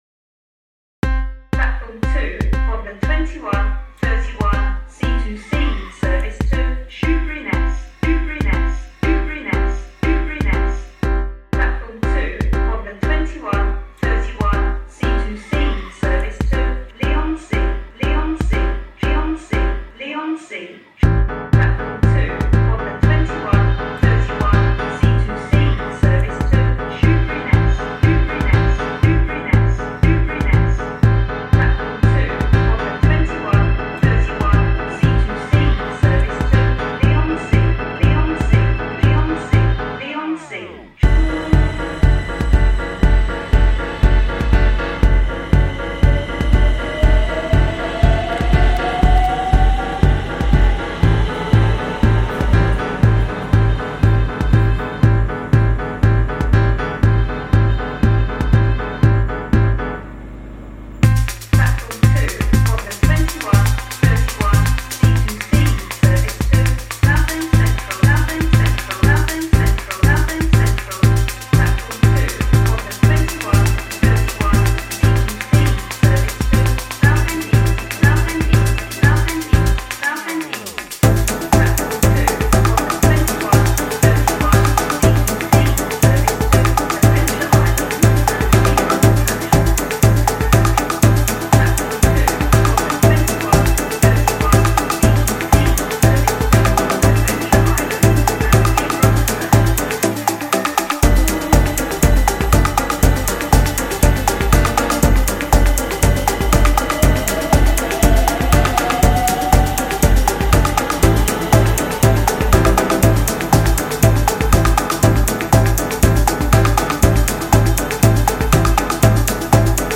Part of the Until We Travel project to map and reimagine the sounds of transport and travel in a pre-pandemic and pandemic world.